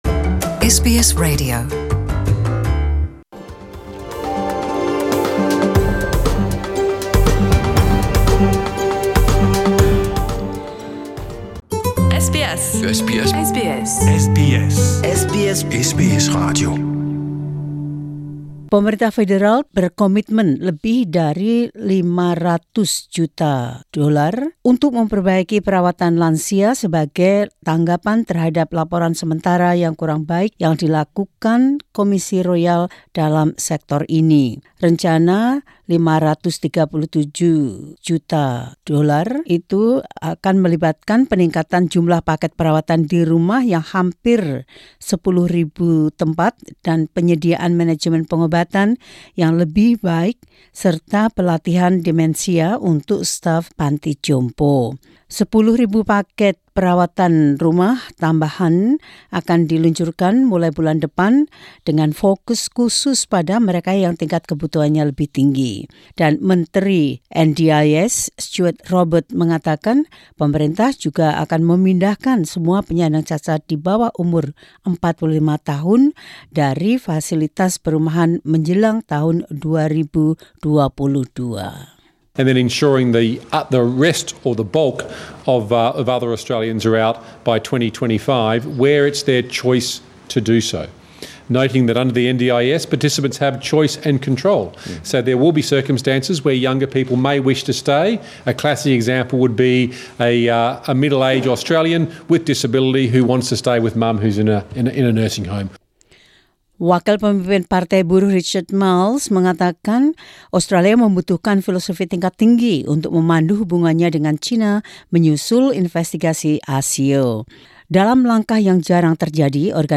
SBS Radio News in Indonesian 25 Nov 2019.
Warta Berita Radio SBS dalam Bahasa Indonesia 25 Nov 2019.